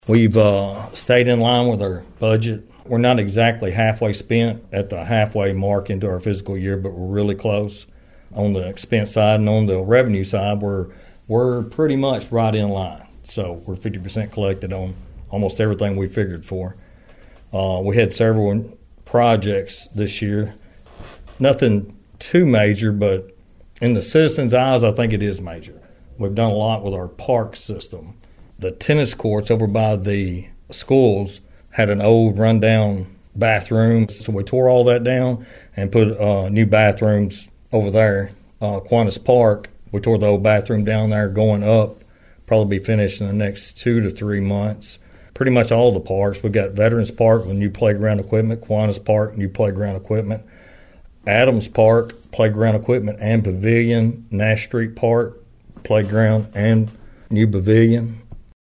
McTurner gave Thunderbolt News some highlights of the past calendar year.(AUDIO)